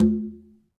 Quinto-HitN_v2_rr1_Sum.wav